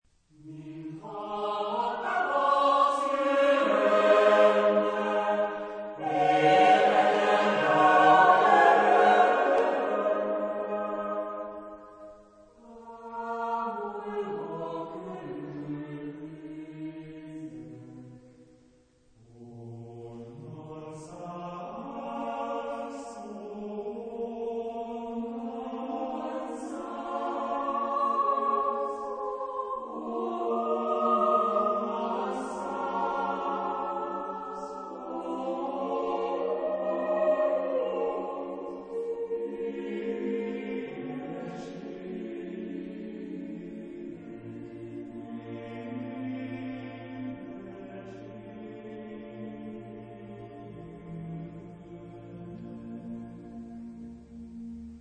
Genre-Style-Forme : Profane ; Lyrique ; Chœur
Type de choeur : SATB  (4 voix mixtes )